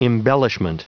Prononciation du mot embellishment en anglais (fichier audio)
embellishment.wav